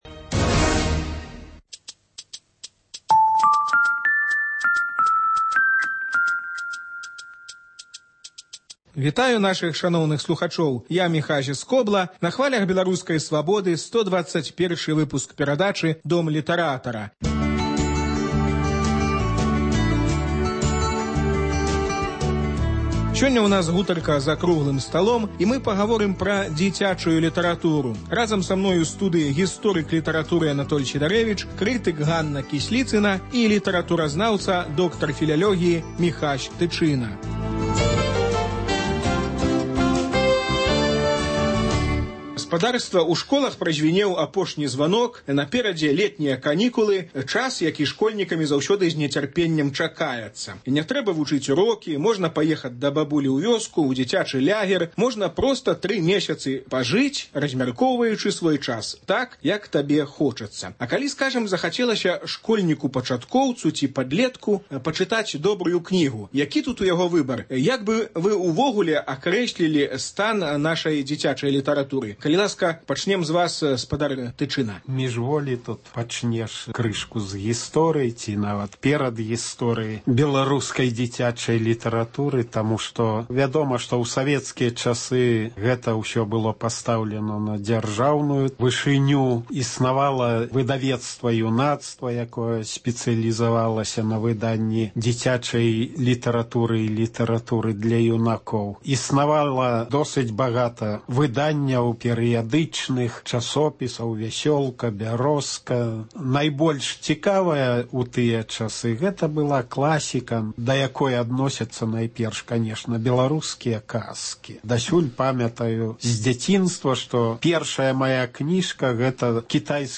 Круглы стол на тэму "Літаратура для дзяцей"